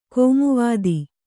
♪ kōmuvvādi